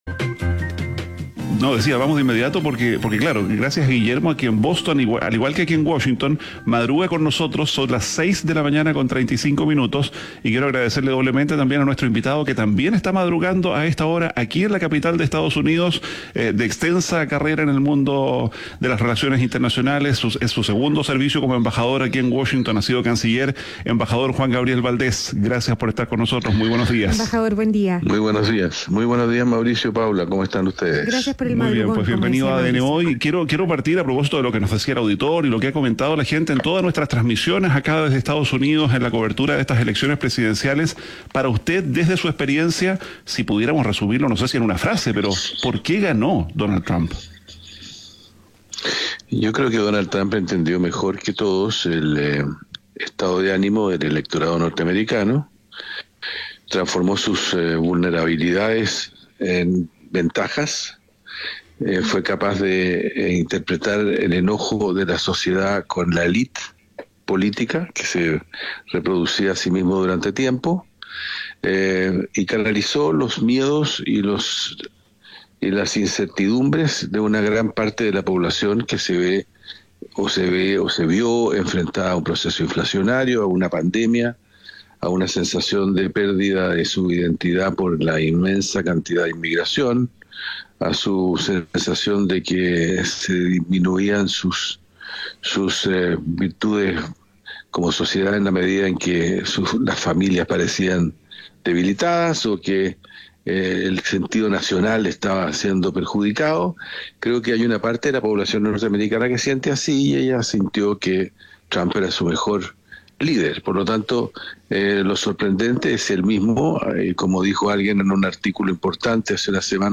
ADN Hoy - Entrevista a Juan Gabriel Valdés, embajador de Chile en Estados Unidos